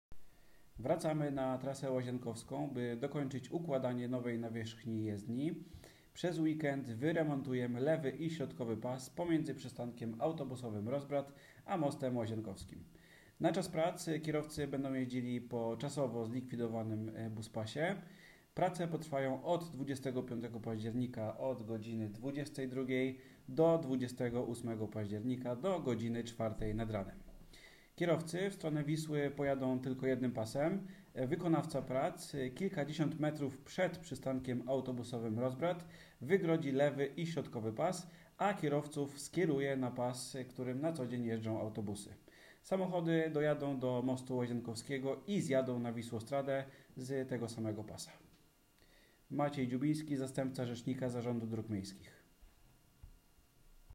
Trasa-Lazienkowska-frezowanie.m4a